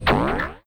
balloon_bounce1.wav